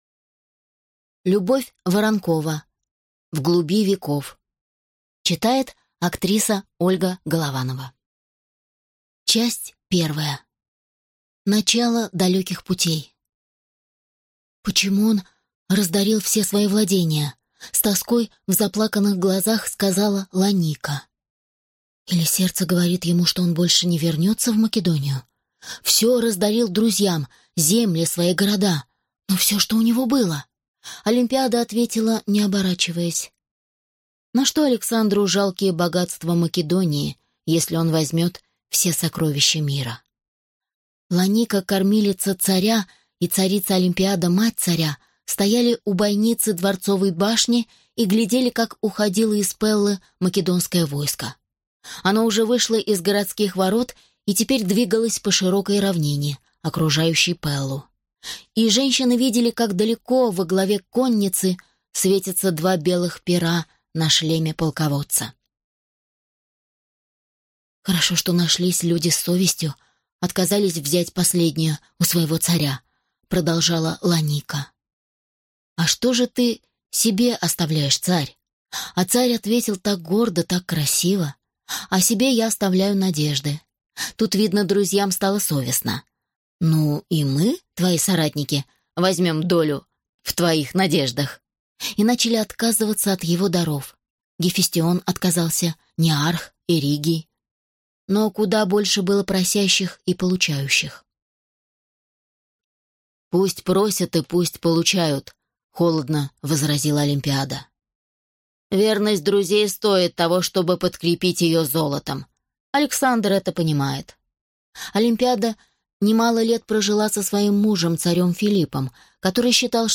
Аудиокнига В глуби веков | Библиотека аудиокниг
Прослушать и бесплатно скачать фрагмент аудиокниги